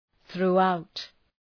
Προφορά
{ɵru:’aʋt}
throughout.mp3